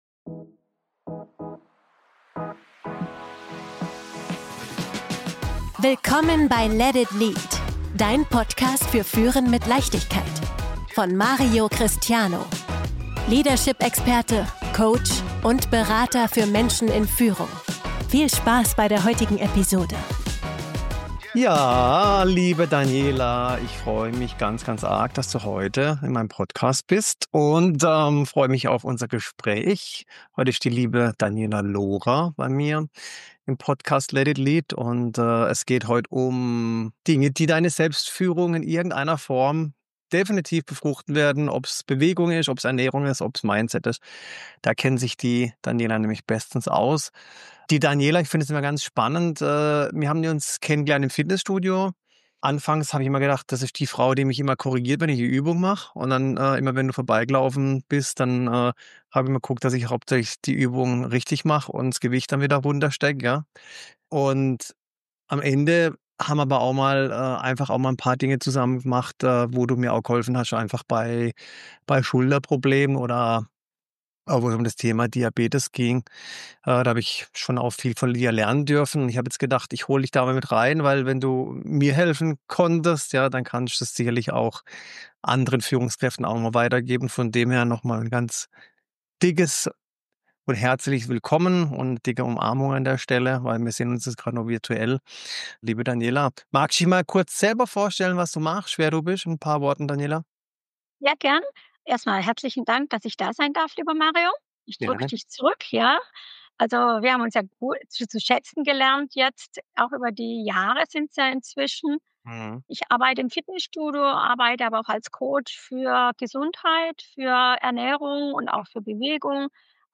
Ein Gespräch für alle, die Verantwortung tragen.